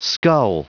Prononciation du mot skull en anglais (fichier audio)
skull.wav